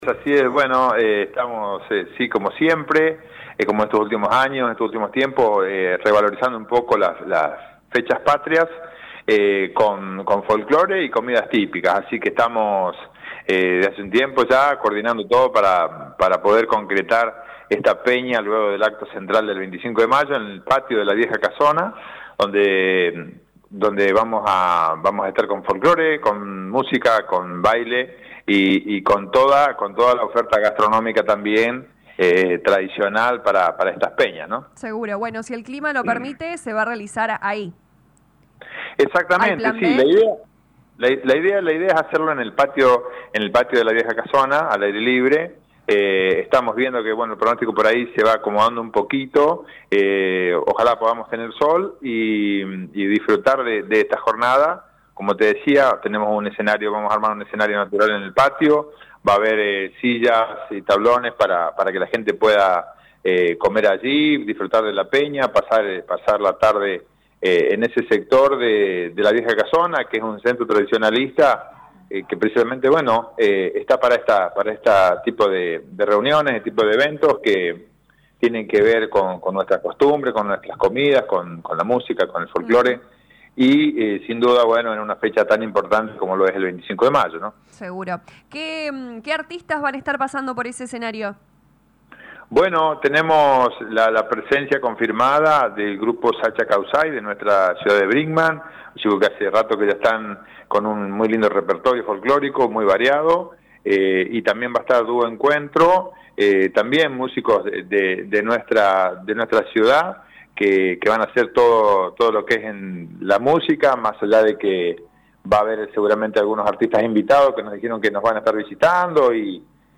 Lo confirmó el director de Cultura y Actividades Artísticas Germán Argañaráz en diálogo con LA RADIO 102.9 FM.